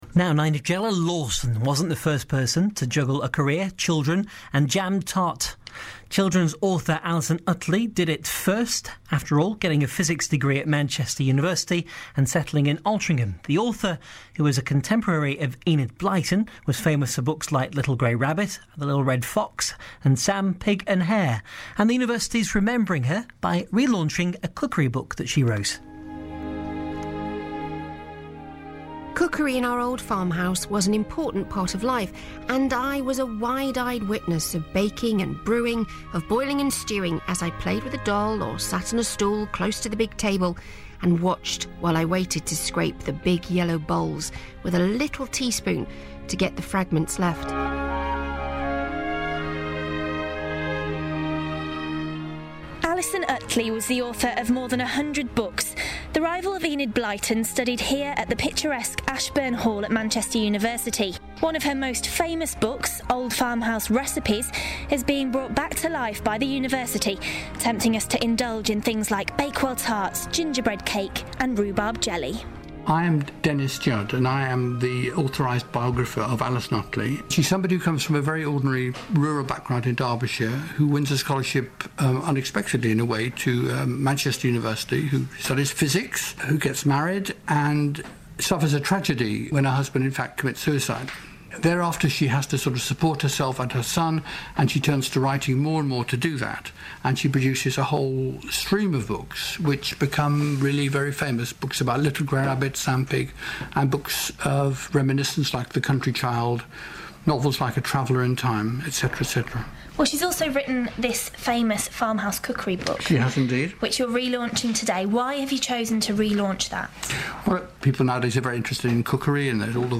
BBC Radio Manchester Interview 28 May 2010